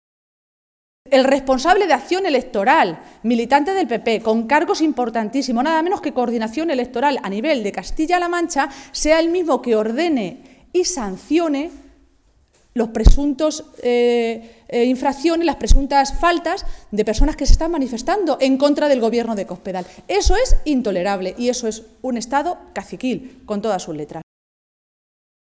Cristina Maestre, vicesecretaria y portavoz del PSOE de Castilla-La Mancha
Cortes de audio de la rueda de prensa